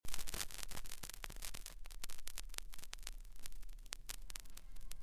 Die nebestehenden Beispiele sollen illustrieren, welche Reinigungergebnisse eine Plattenwaschmaschine erzielen kann. Die Beispiele lassen sich am besten mit einem Kopfhörer vergleichen.